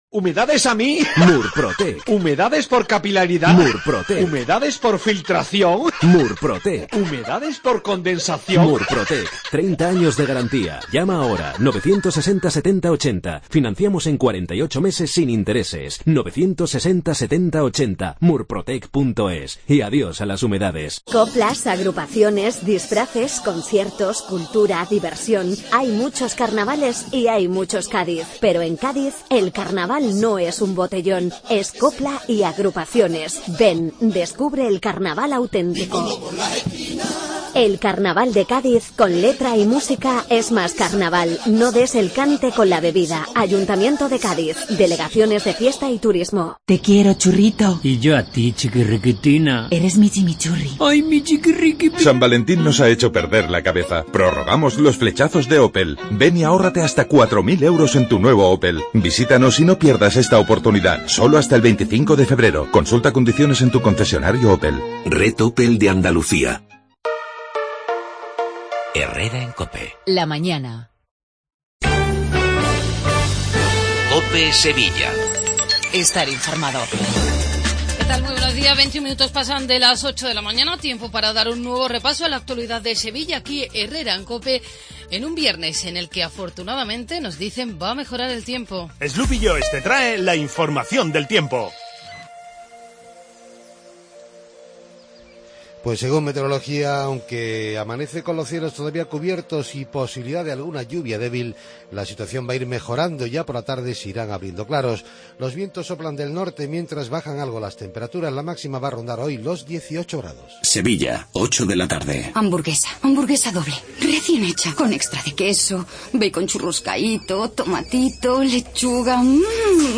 INFORMATIVO LOCAL MATINAL 8:20